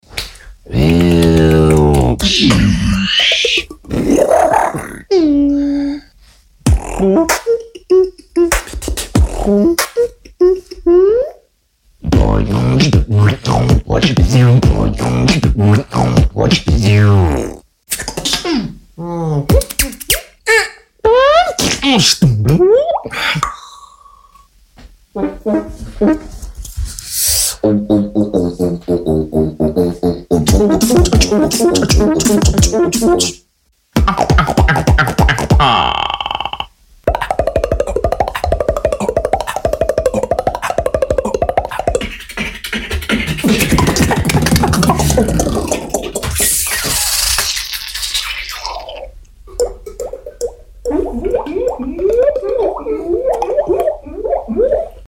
Alien house party sound effects free download
Mp3 Sound Effect